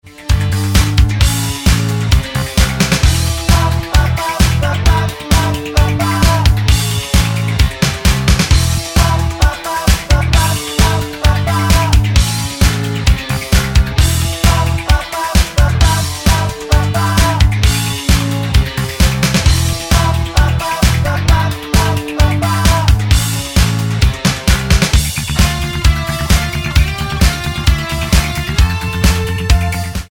--> MP3 Demo abspielen...
Tonart:Dm mit Chor